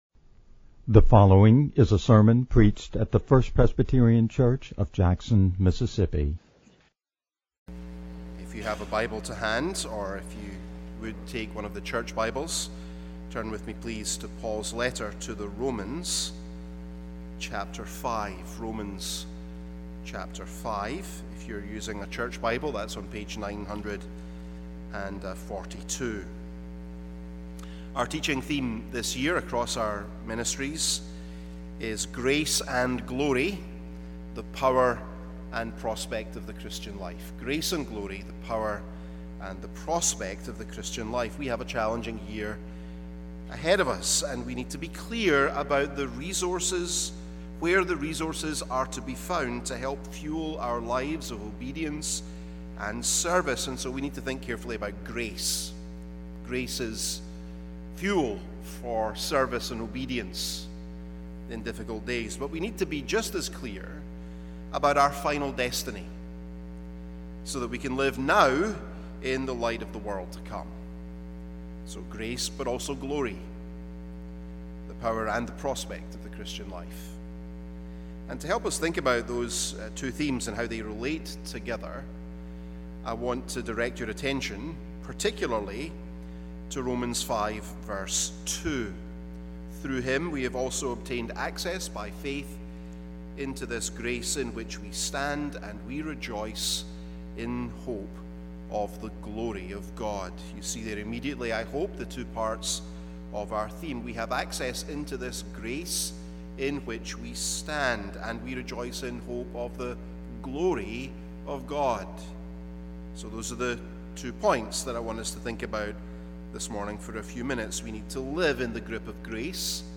First-Presbyterian-Church-Staff-Convocation-Grace-and-Glory-IE-8_8_222.mp3